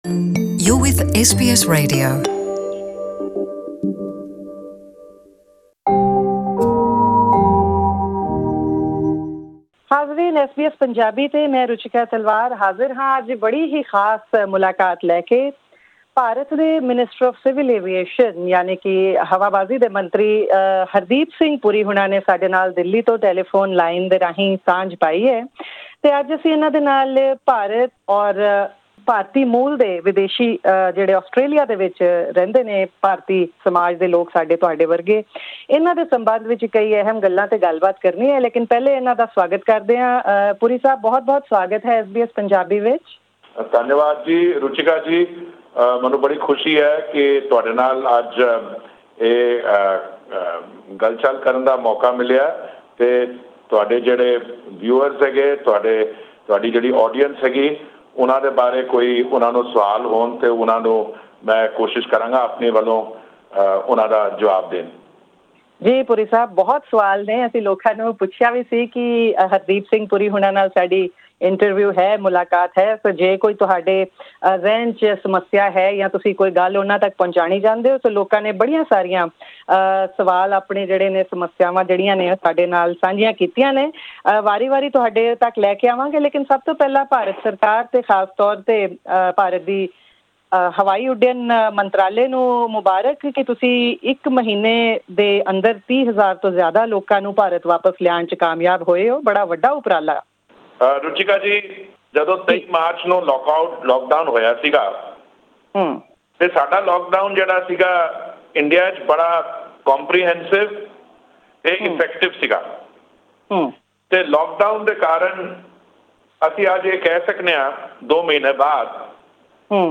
In a comprehensive interview with SBS Punjabi, India’s Minister for Civil Aviation Hardeep Singh Puri talks about the challenges thrown by the COVID-19, Vande Bharat (repatriation of Indians stranded overseas), Air India’s financial distress and his transition from an Indian diplomat to minister.